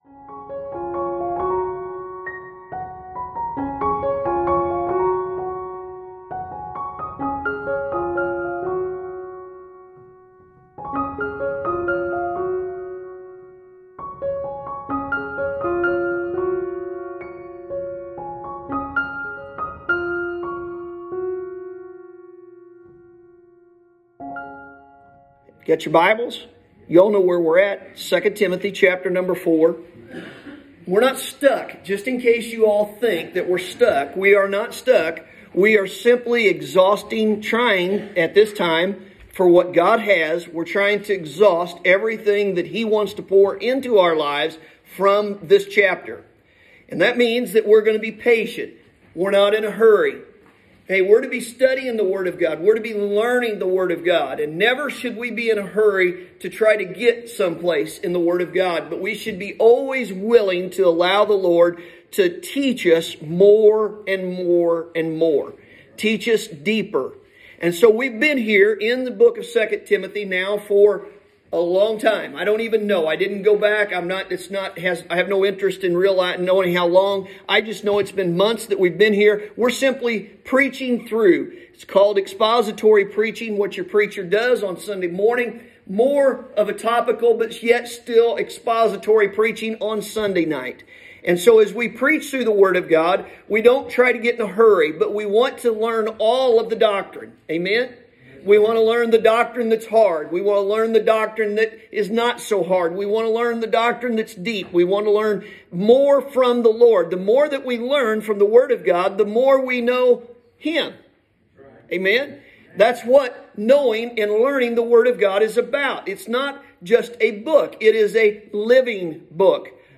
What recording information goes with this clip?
Sunday Morning – March 7th, 2021